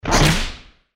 target_launch.ogg